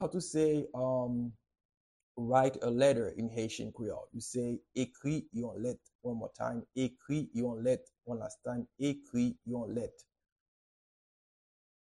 Pronunciation and Transcript:
How-to-say-Write-a-Letter-in-Haitian-Creole-Ekri-yon-let-pronunciation.mp3